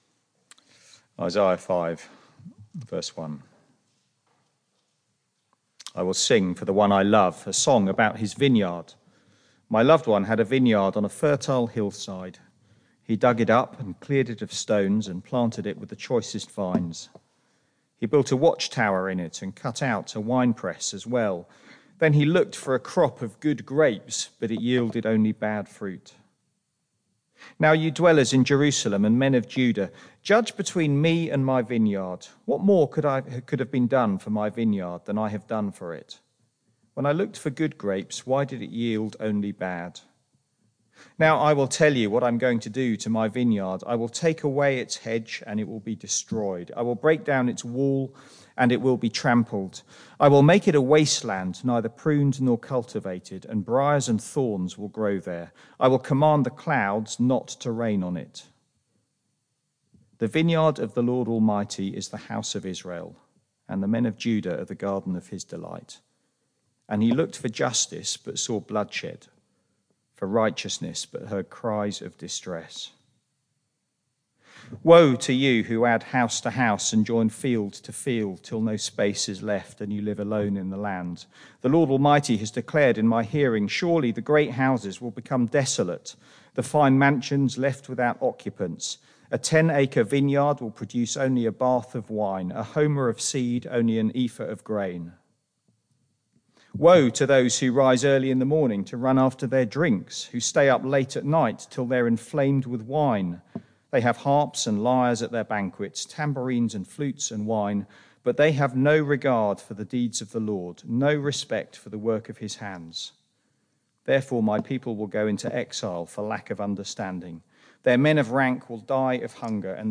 Media for Barkham Morning Service on Sun 02nd Oct 2022 10:00
Reading & Sermon